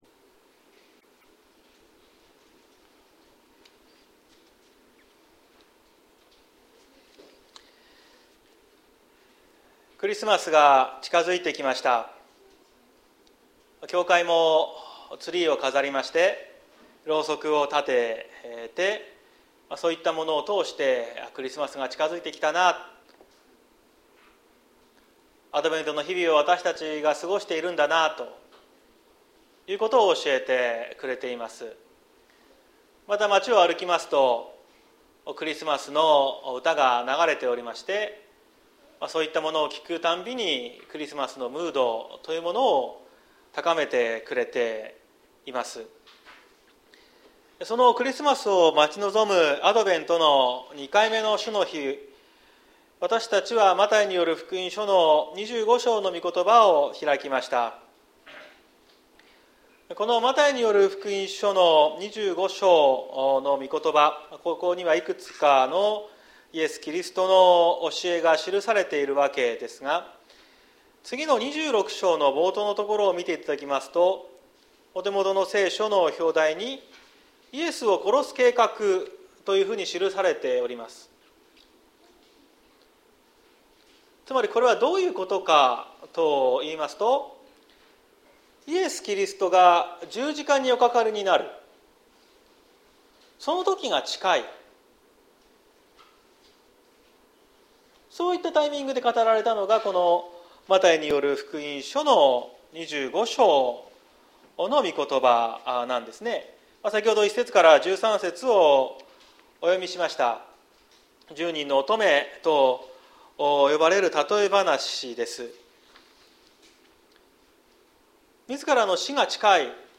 綱島教会。説教アーカイブ。
毎週日曜日の10時30分から神様に祈りと感謝をささげる礼拝を開いています。